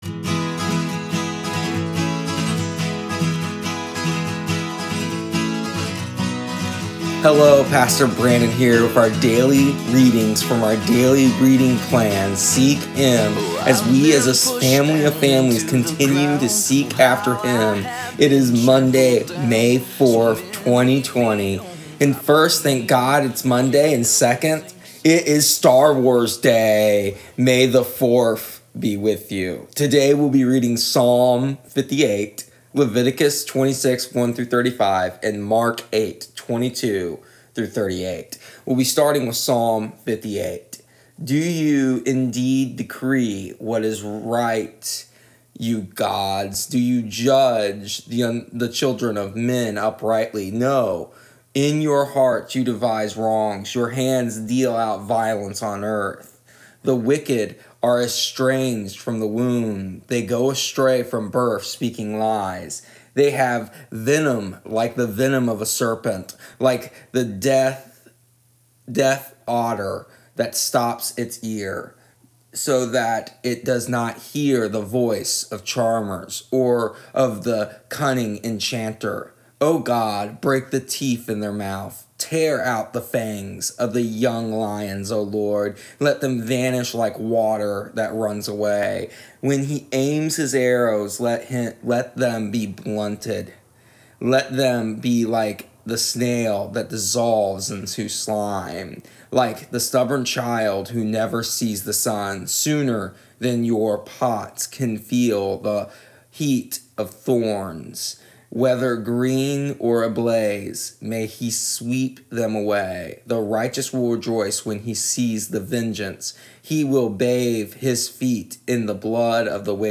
Here are our readings from our daily reading plan in audio straight from our home to yours. Today we discuss the Mark passage an talk about how sometimes it takes more than once to open the eyes of the blind.